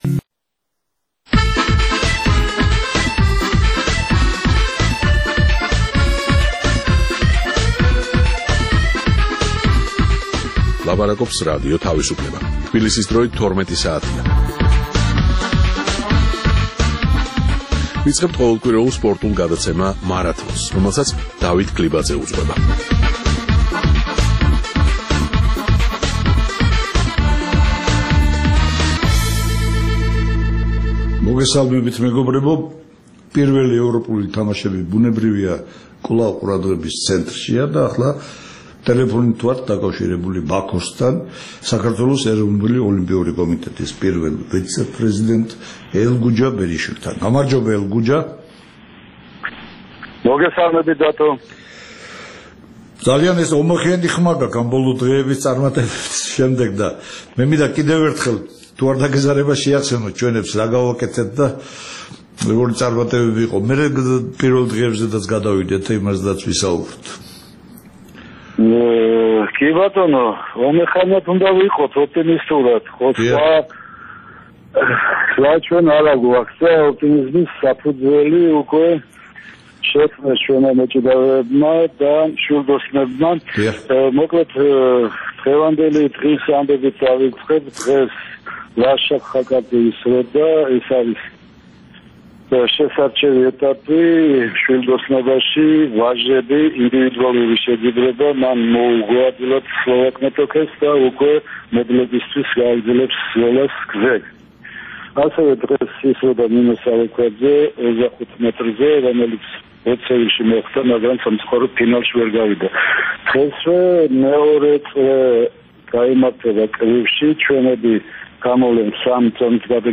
ყოველკვირეულ სპორტულ პროგრამაში გვექნება სატელეფონო ჩართვა ბაქოდან